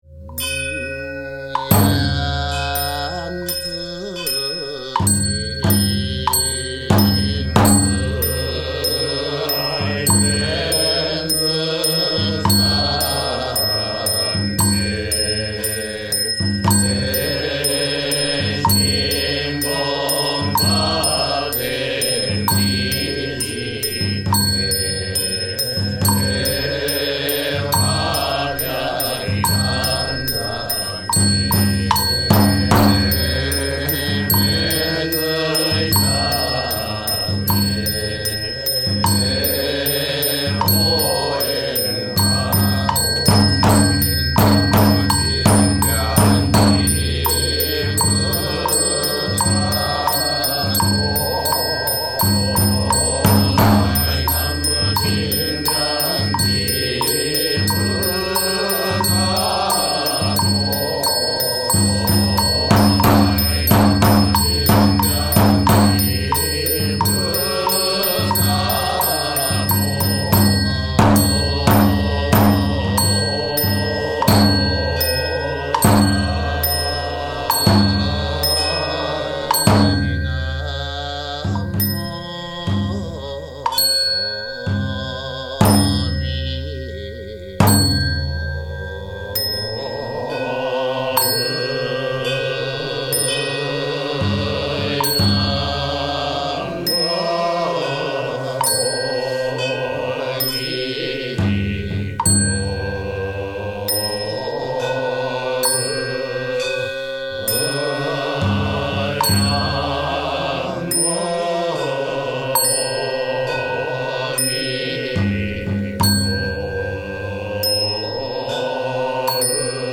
実は、黄檗宗（元は臨済宗黄檗派と称していました）のお経は、中国明時代の発音と旋律で唱えるため、非常に音楽的なお経です。木魚や太鼓、引磬という鐘も２種類あり、下記のような多数の鳴り物を使います。
中国民代のお経をそのまま継承している黄檗宗のお経、「梵唄（ぼんばい）」は、どこか懐かしい旋律を持っています。
癒される旋律ですよ。